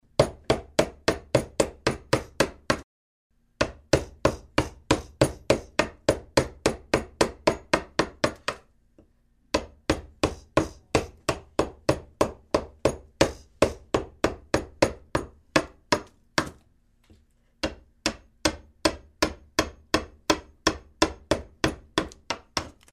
Звуки гвоздей, шурупов
Звук ударов молотка